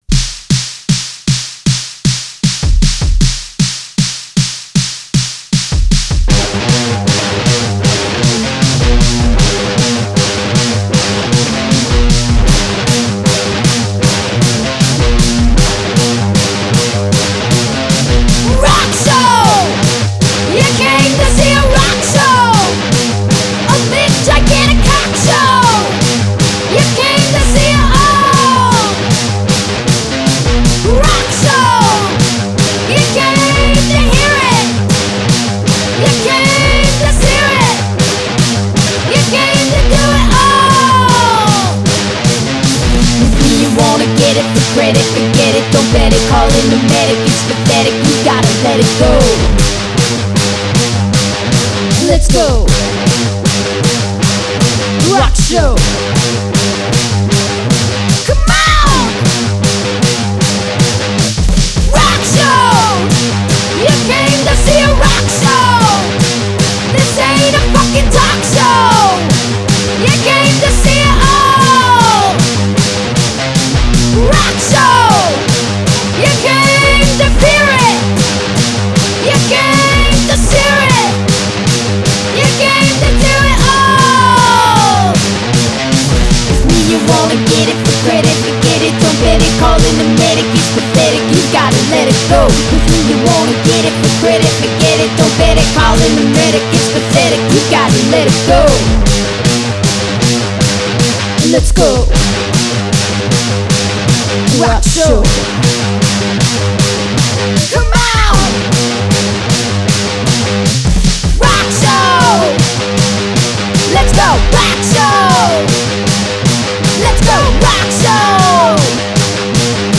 the drums
Listen to these, well BANGING.